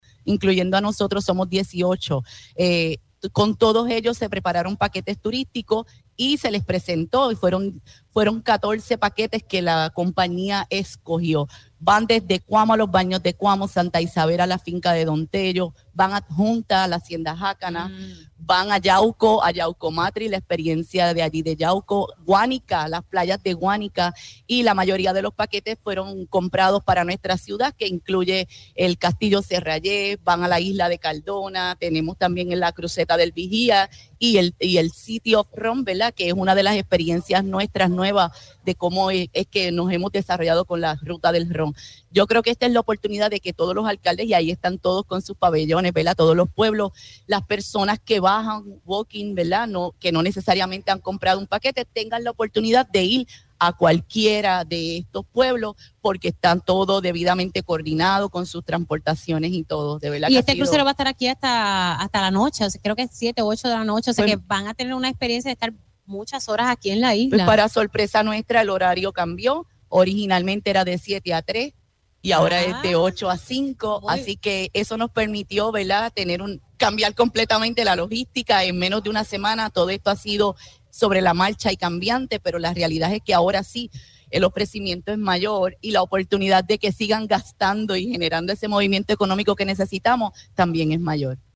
Por su parte, en entrevista para este espacio la alcaldesa Marlese Sifre se mostró emocionada con el acontecimiento.